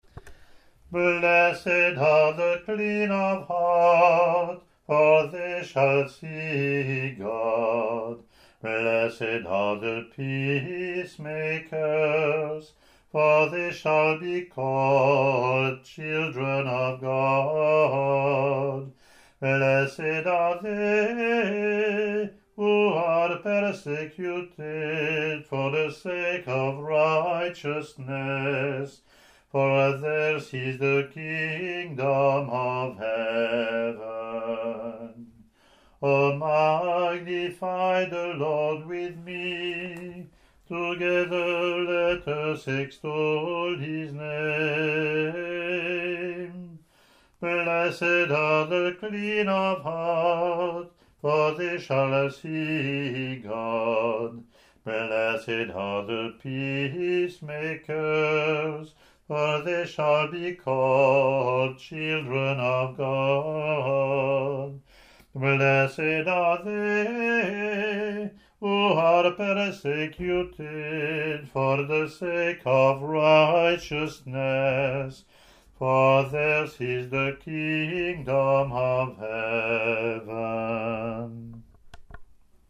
Click to hear Communion (
English antiphon – English verse